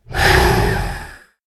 beast_roar_aggresive.ogg